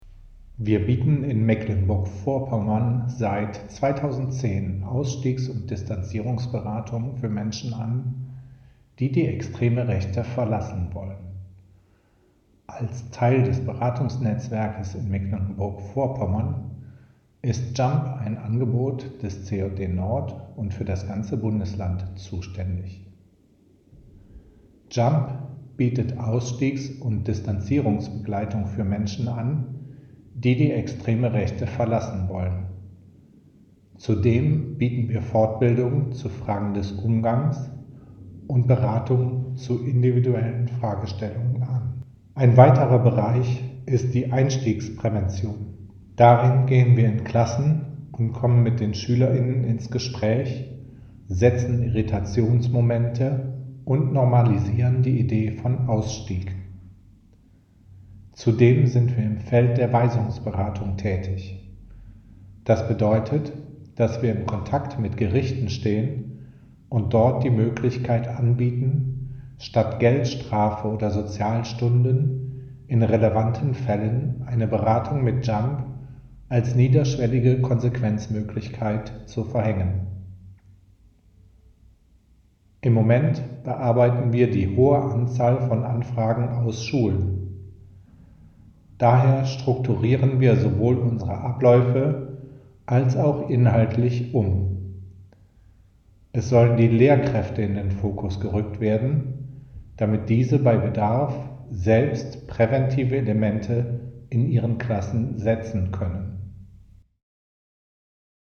Sie stellen sich in einem kurzen Hörbeitrag selbst vor.